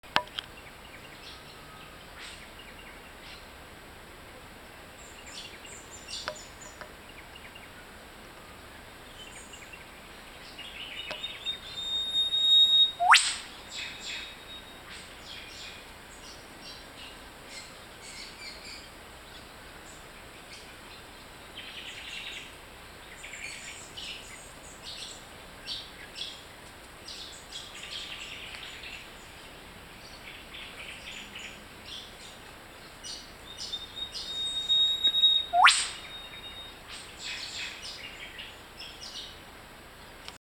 Whip Bird Call